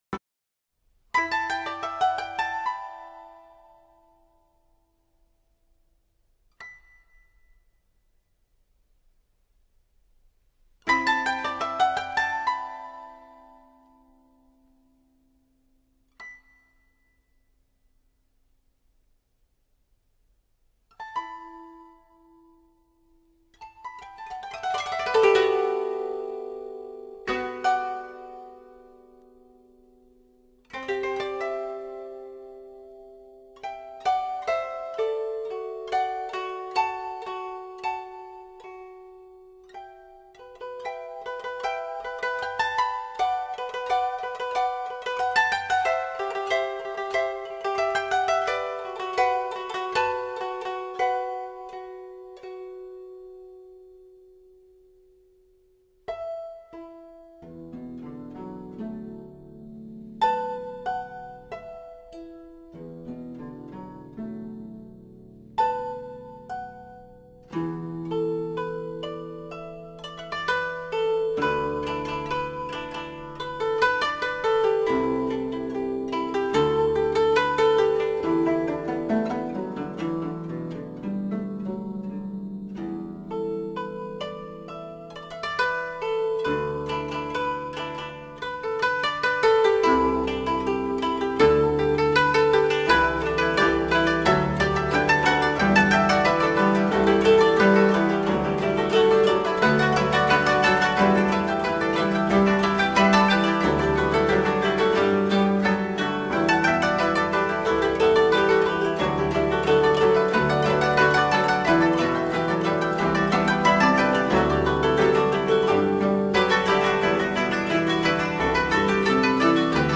Koto